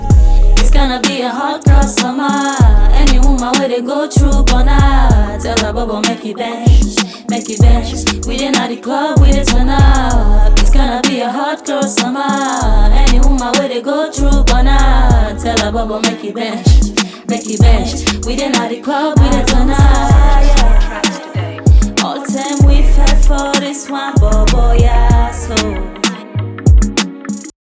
R&B Afrobeat - Wanborbor_8fm4RFbljy.wav